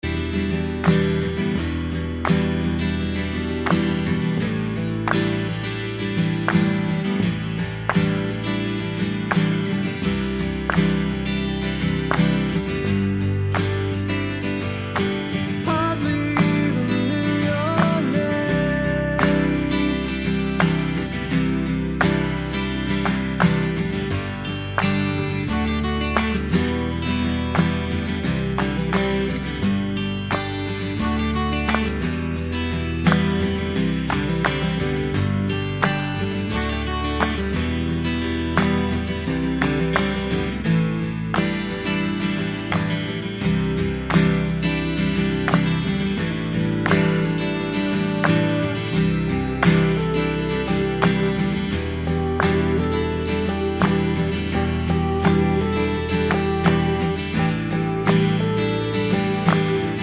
no Backing Vocals Indie / Alternative 4:07 Buy £1.50